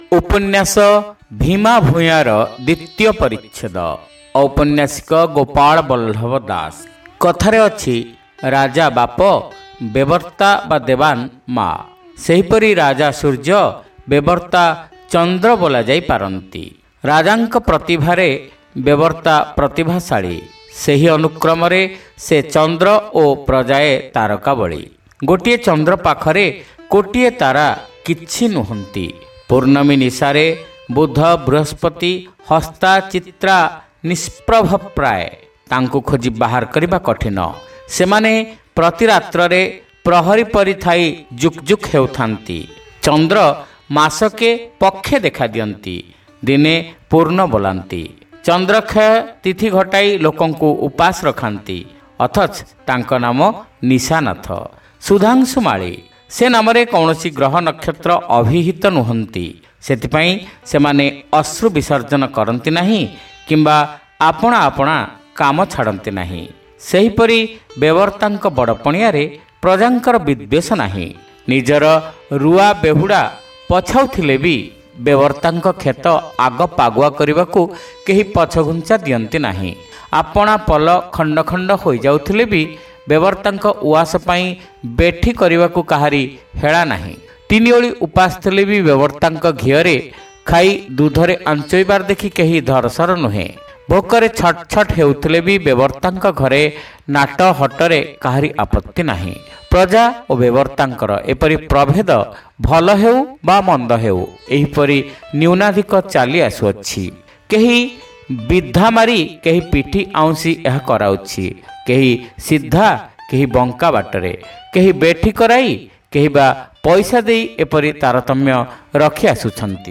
ଶ୍ରାବ୍ୟ ଉପନ୍ୟାସ : ଭୀମା ଭୂୟାଁ (ଦ୍ୱିତୀୟ ଭାଗ)